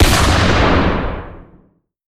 Sniper_Rifle1.ogg